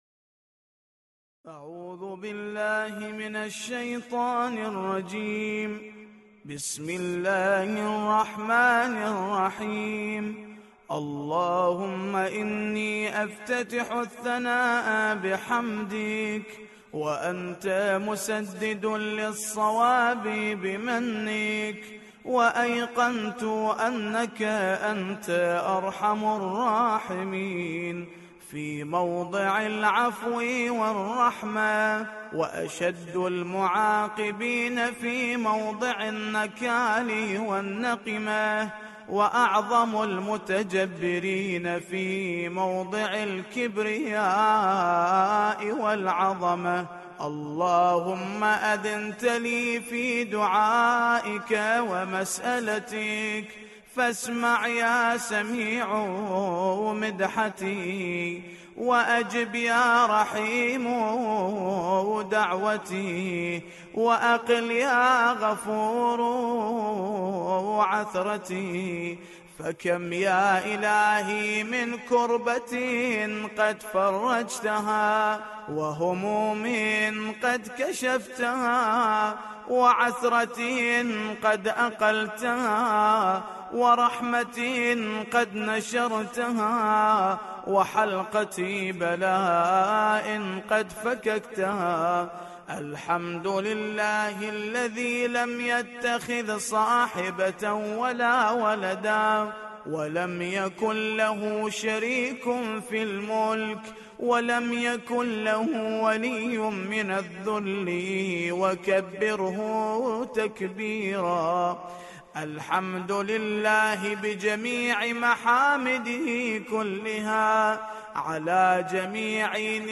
ملف صوتی دعاء الإفتتاح